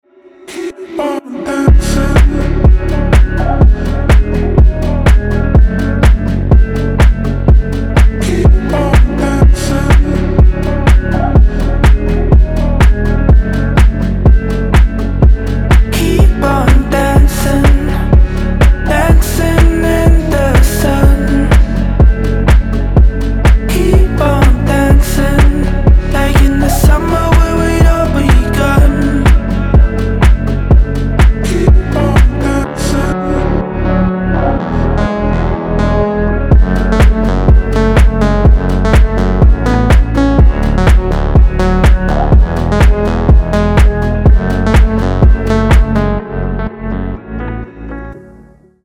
• Качество: 320, Stereo
deep house
спокойные
теплые
нежные